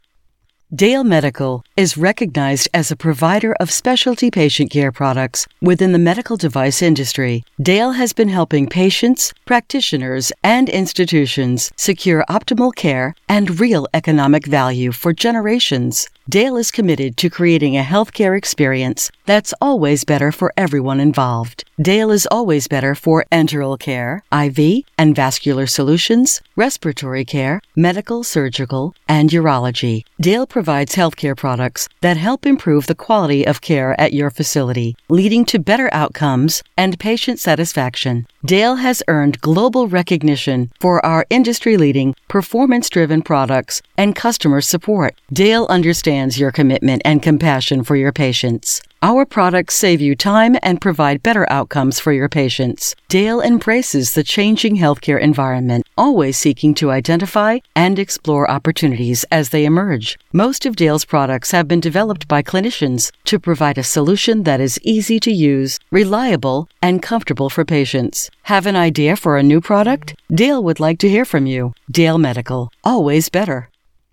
Female
My voice is very versatile, warm, conversational, and real.
Audiobooks
Words that describe my voice are Warm, Conversational, Sophisticated.
All our voice actors have professional broadcast quality recording studios.